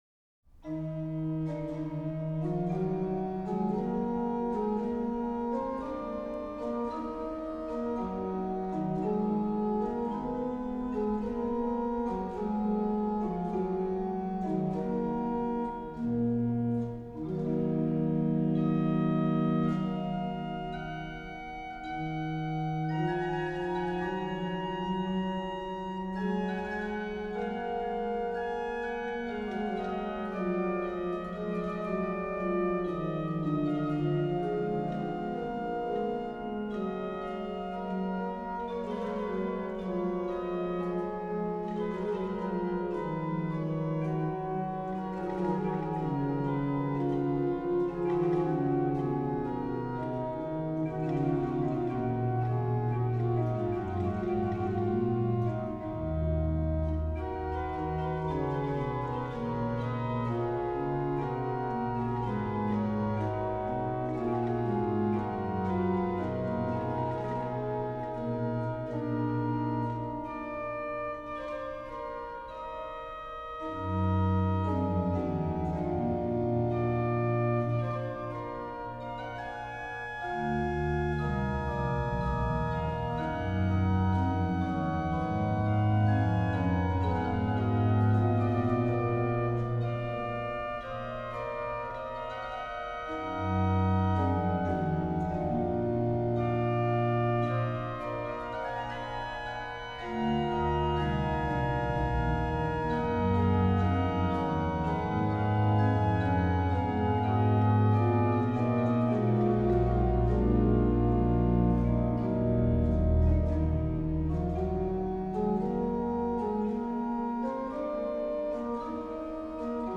HW: Viol8
Ped: Pr16